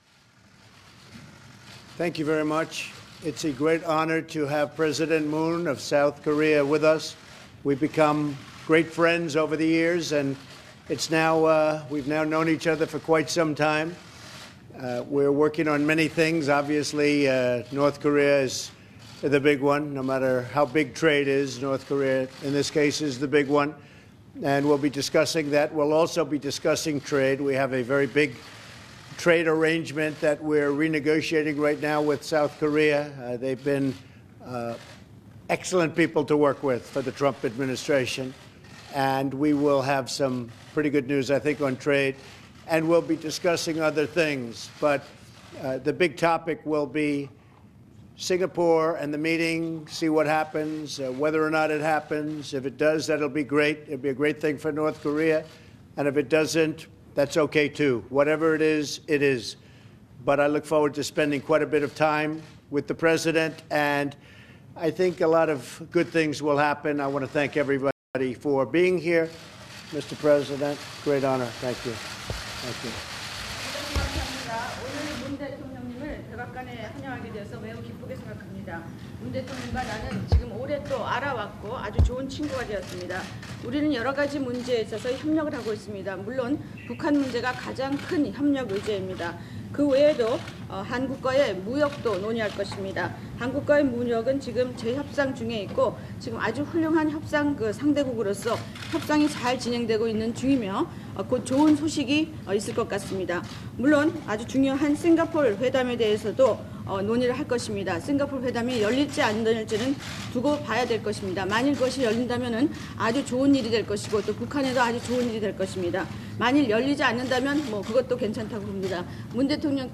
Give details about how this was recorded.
Broadcast 2018 May 22